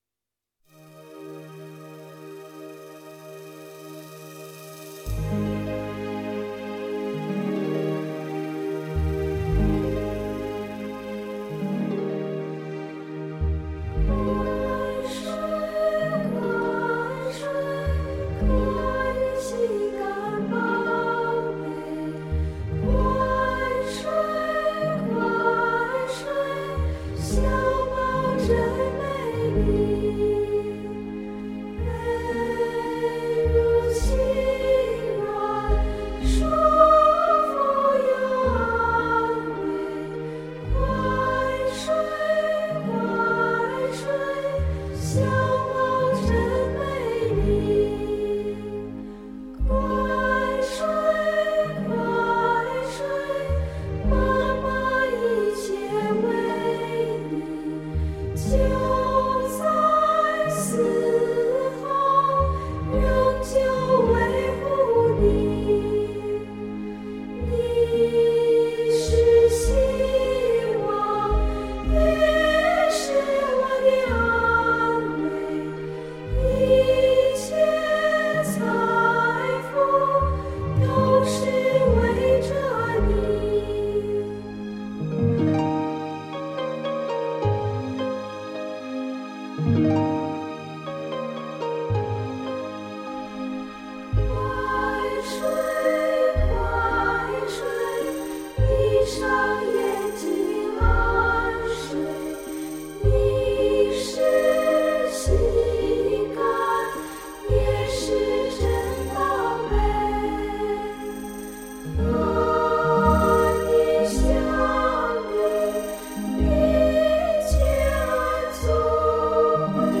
收录世界各地知名的摇篮曲 ，让您感受不同国度中的相同母爱